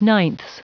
Prononciation du mot ninths en anglais (fichier audio)
Prononciation du mot : ninths